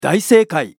男性ボイス | 無料 BGM・効果音のフリー音源素材 | Springin’ Sound Stock
大正解1.mp3